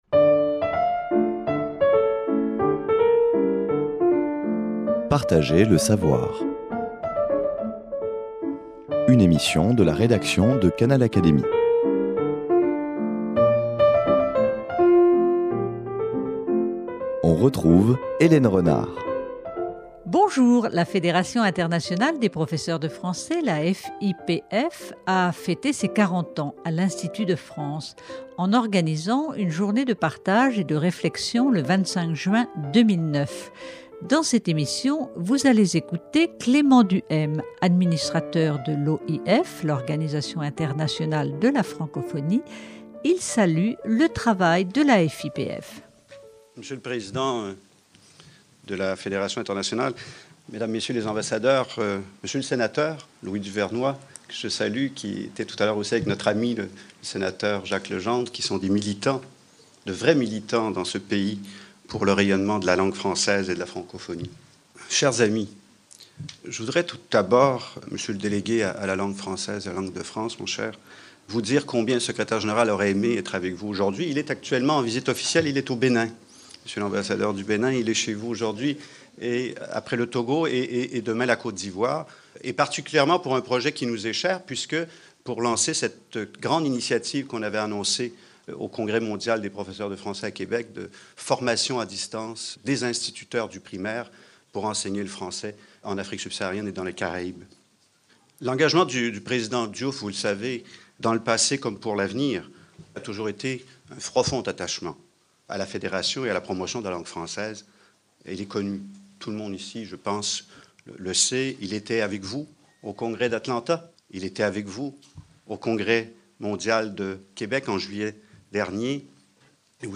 A l’occasion des 40 ans de la Fédération internationale des professeurs de français
Le discours de Clément Duhaime, s'il fut bref, fut un chaleureux encouragement pour les participants présents en ce 25 juin 2009 à l'Institut de France pour fêter les 40 ans de la FIPF.
Clément Duhaime l'a exprimé avec sa force de conviction : l'appui de l'OIF à la FIPF (forte de ses 80.000 enseignants regroupés en 172 associations dans 130 pays), ne se démentira pas.